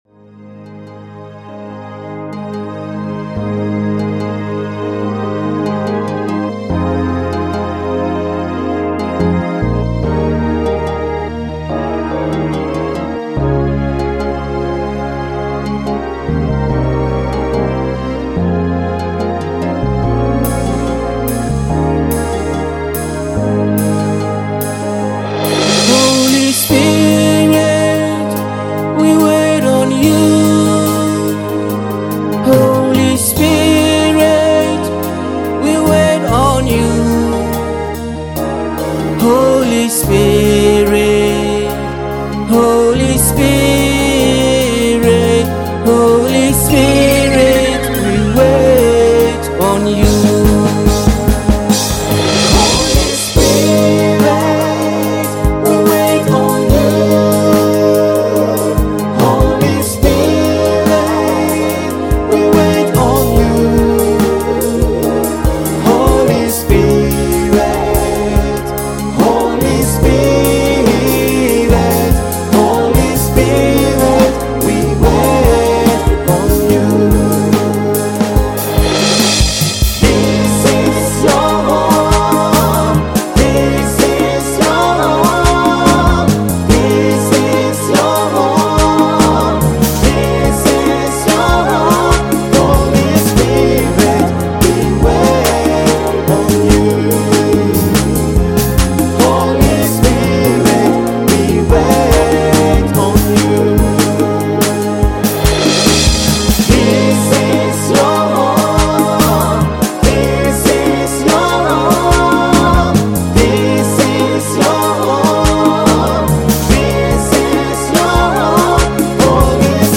GospelMusic
Talented Ghanaian Gospel singer